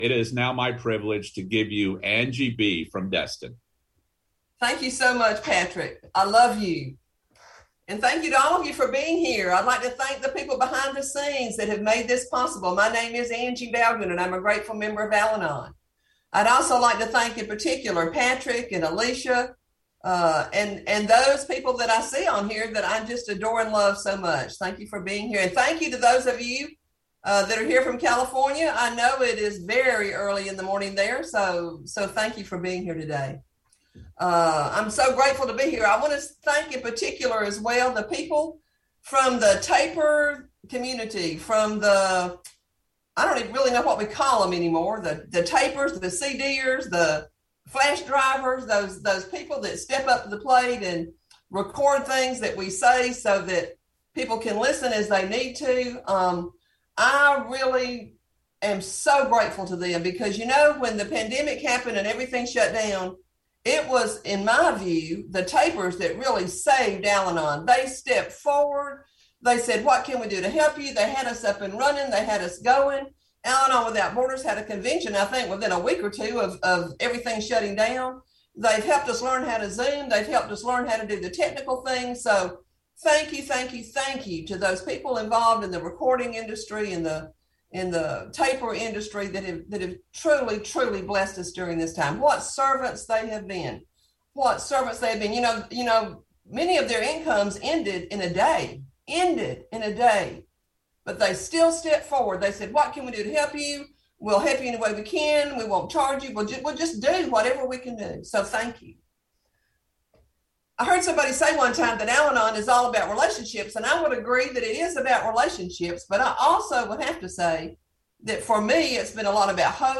Georgia State Al-Anon/Alateen Convention - 2021 - Virtual